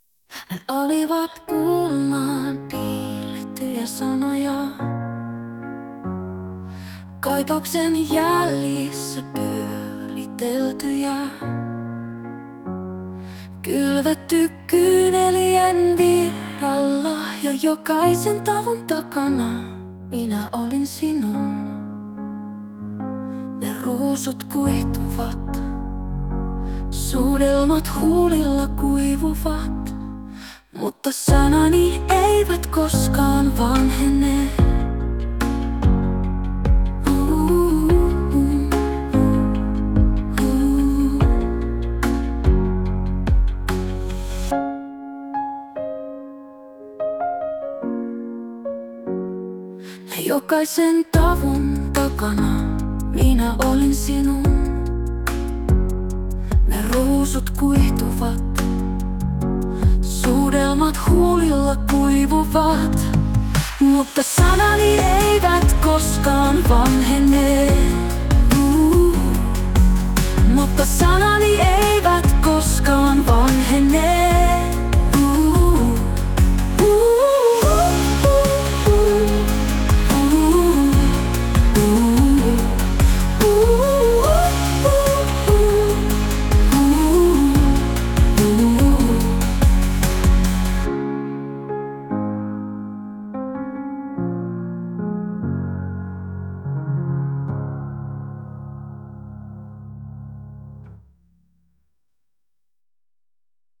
tekoälyllä tehty biisi